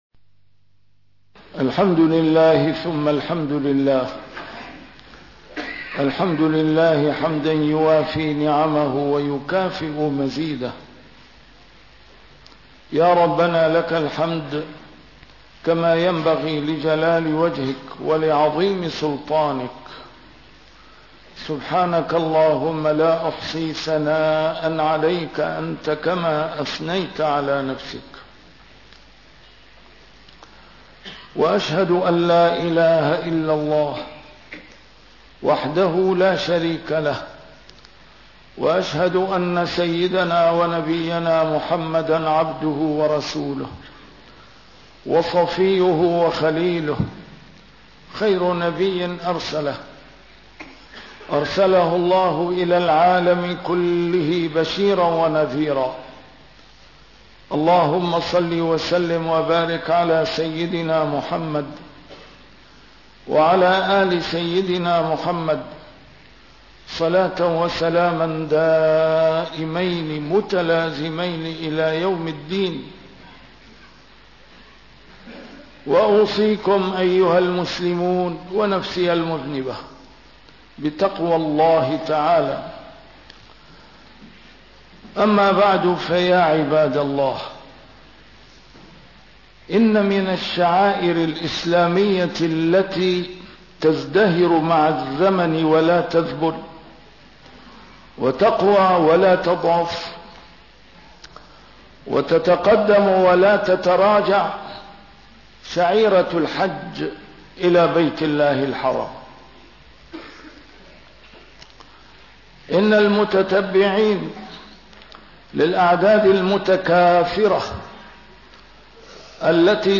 A MARTYR SCHOLAR: IMAM MUHAMMAD SAEED RAMADAN AL-BOUTI - الخطب - الدعاء الدعاء لأمتكم يا حجاج